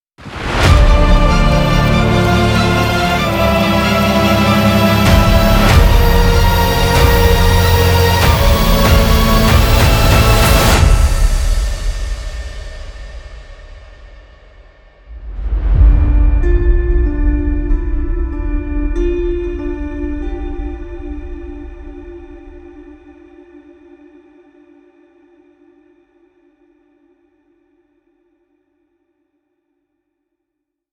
Trailer Hybrid - 30 sec
action adventure background battle brass cinematic dramatic emotional sound effect free sound royalty free Movies & TV